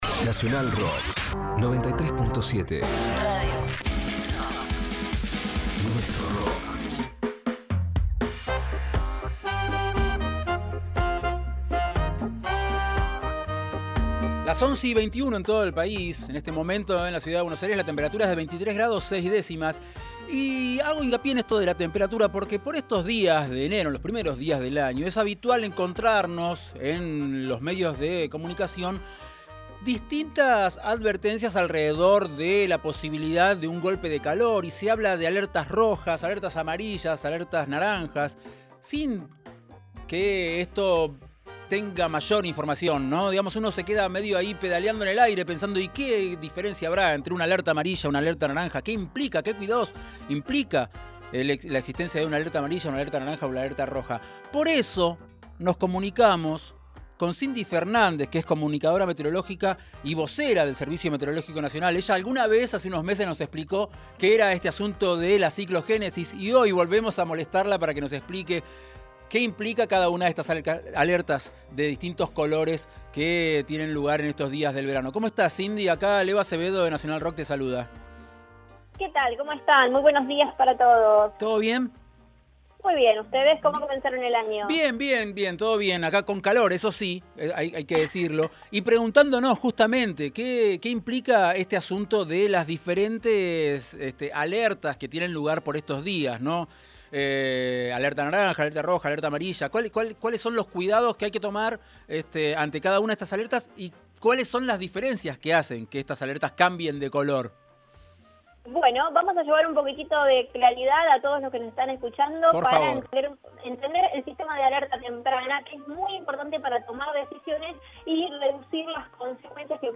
ENTREVISTA Cuál es la diferencia entre alerta amarilla, naranja o roja y cómo enfrentar las temperaturas altas